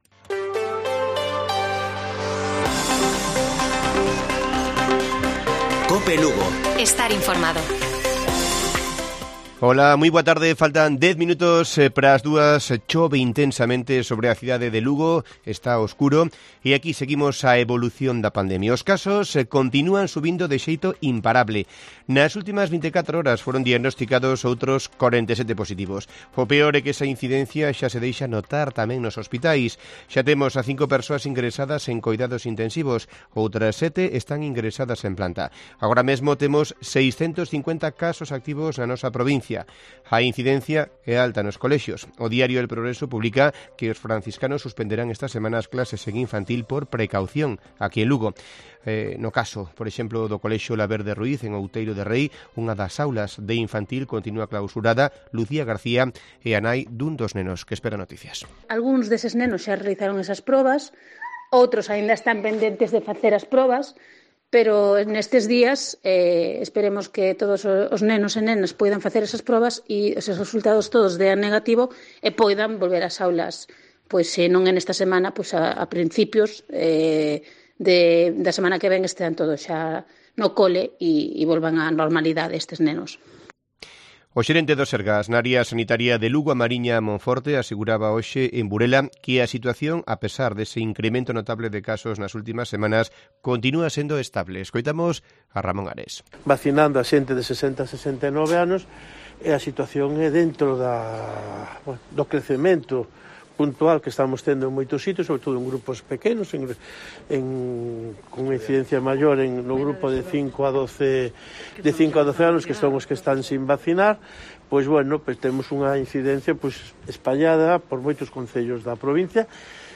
Informativo Mediodía de Cope Lugo. 07 de diciembre. 13:50 horas